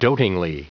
Prononciation du mot dotingly en anglais (fichier audio)
Prononciation du mot : dotingly